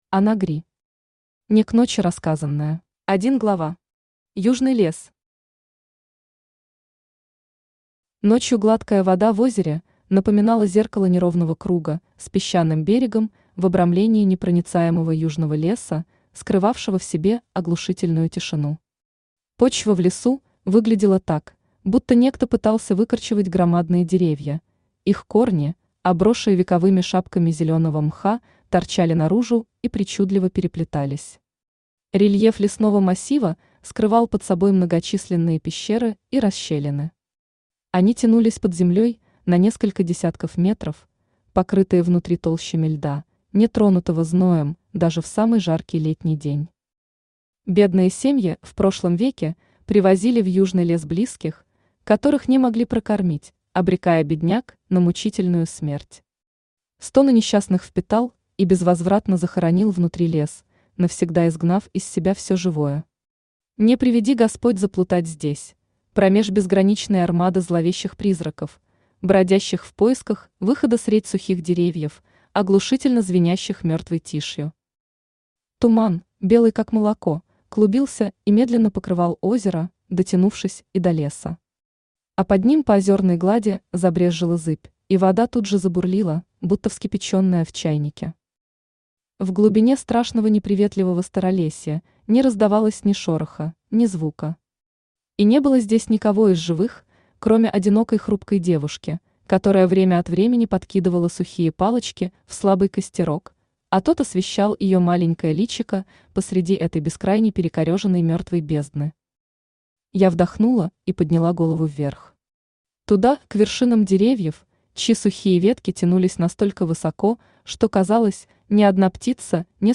Аудиокнига Не к ночи рассказанное…
Автор Ана Гри Читает аудиокнигу Авточтец ЛитРес.